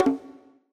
buttonclick.ogg